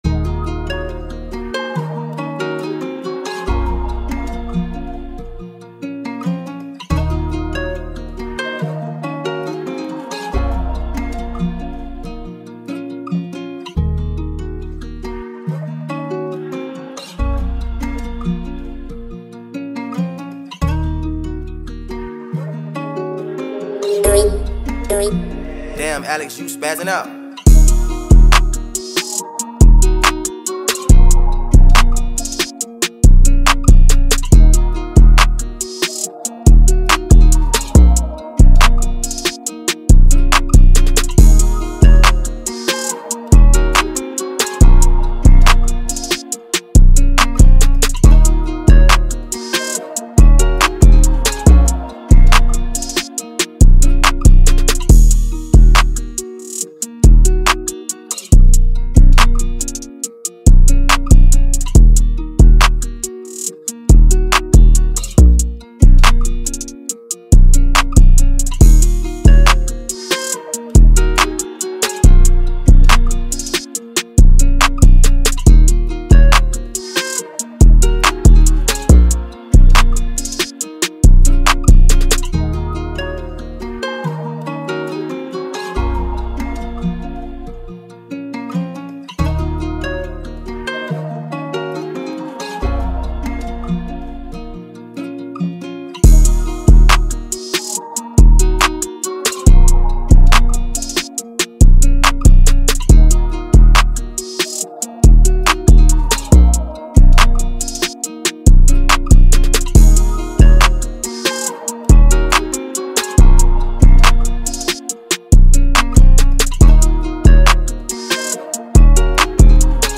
a captivating Freestyle Rap Instrumental
With its alluring melody and dynamic rhythm